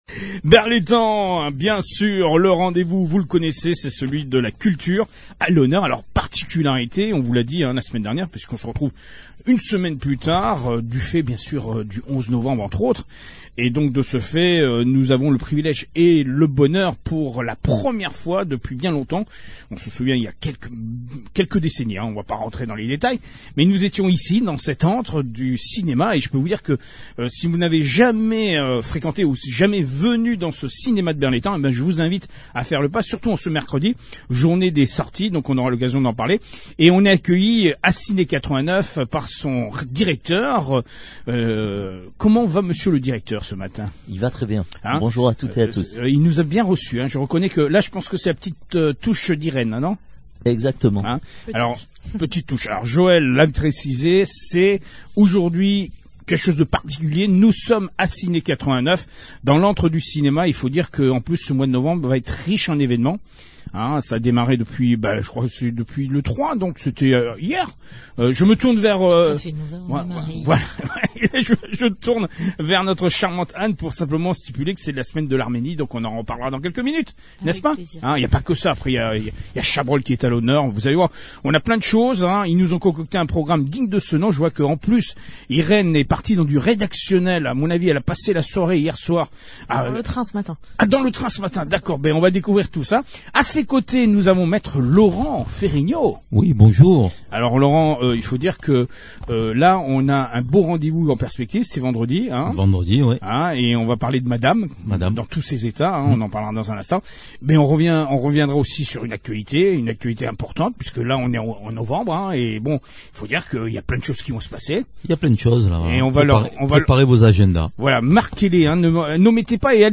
Imaginez : une ambiance douillette et conviviale pour cette nouvelle émission !
Tous, chacun son tour au micro et sur les ondes de Chérie FM, allaient nous concocter un cocktail d’actualités pétillantes !